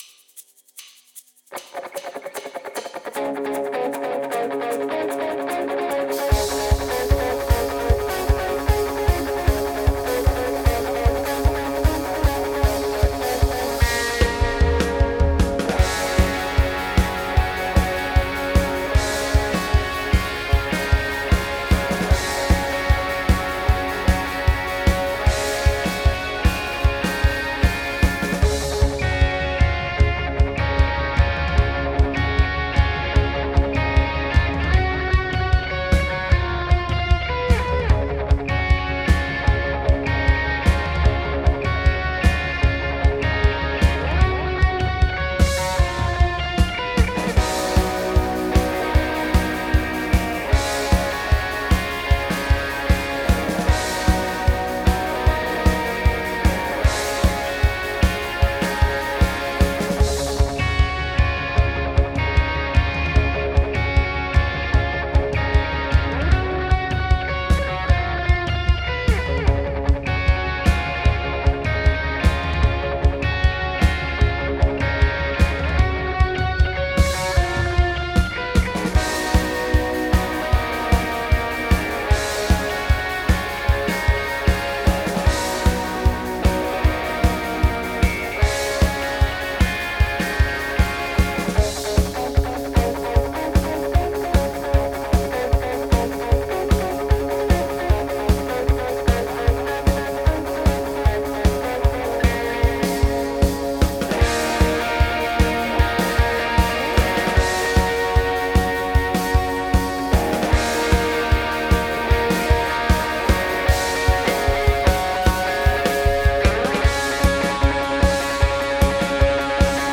Without vocals
Based on the Vertigo Tour